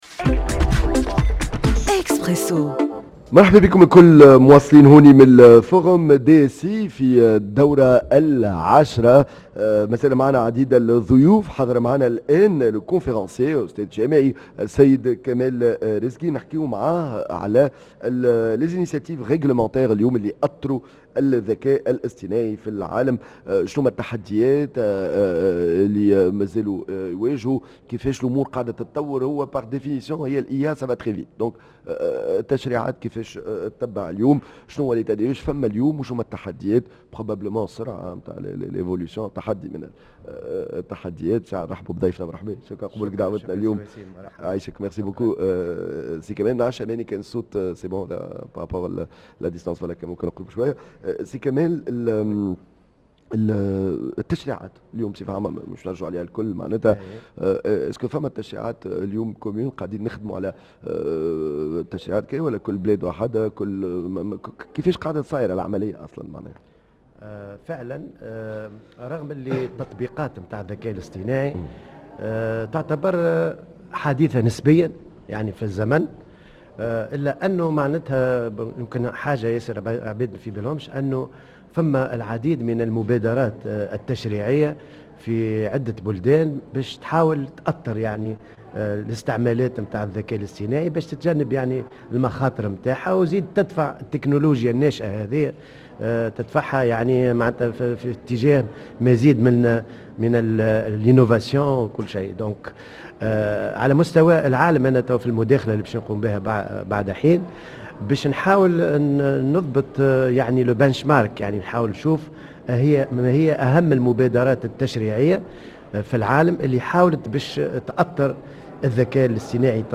dans un plateau spécial en direct de Yasmine El Hammamet